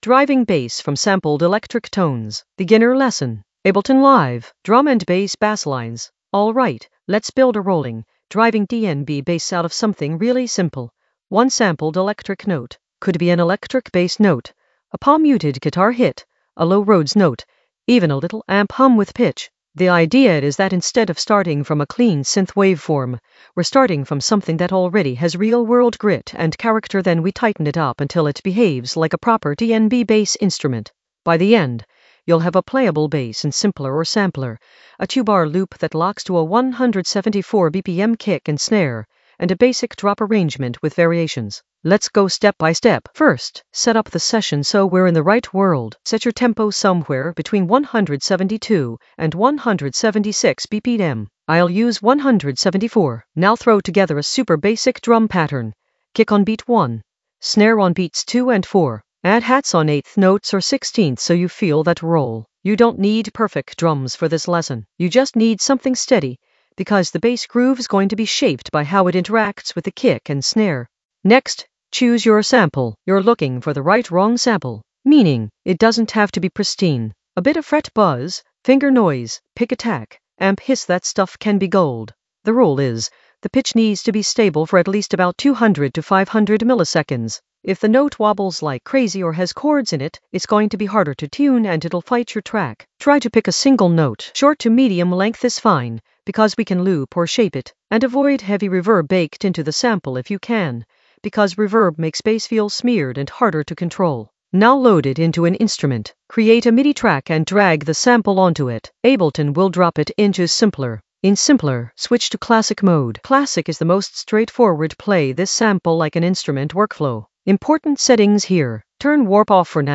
Narrated lesson audio
The voice track includes the tutorial plus extra teacher commentary.
An AI-generated beginner Ableton lesson focused on Driving bass from sampled electric tones in the Basslines area of drum and bass production.
driving-bass-from-sampled-electric-tones-beginner-basslines.mp3